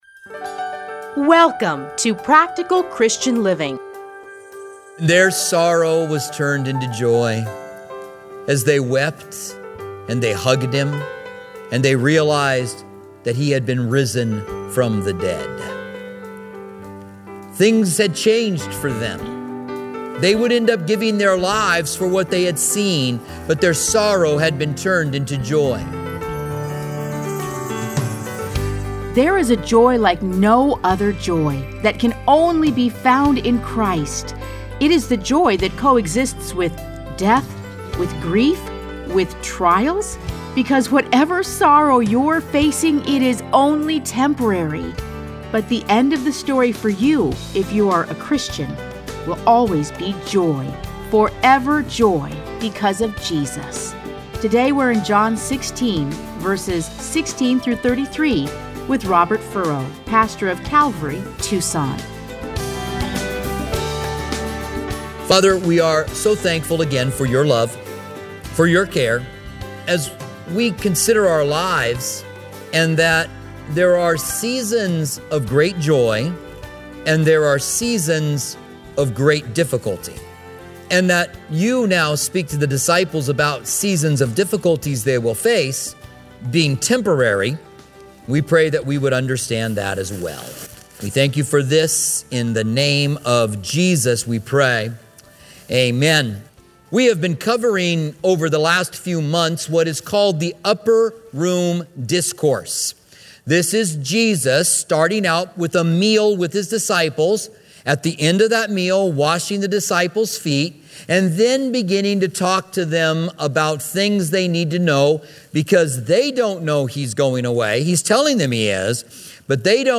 Listen to a teaching from John John 16:16-33 .